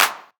013_hfhf_clap&snare_foot.wav